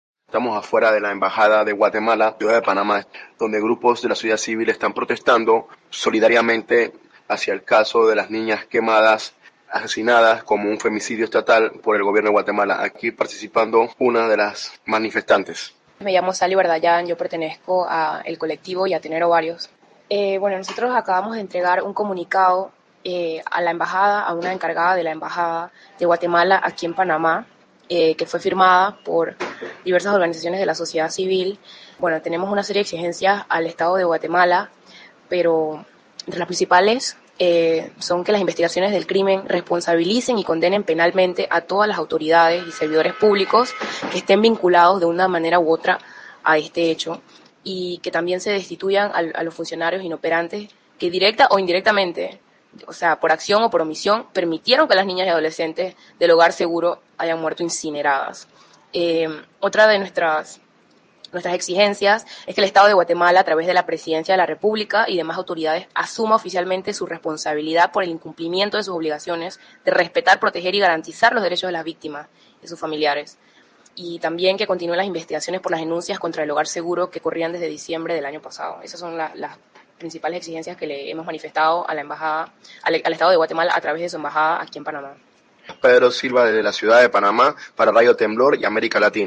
La acción se realizó en la Embajada de Guatemala, en ciudad de Panamá, el martes 14 de marzo.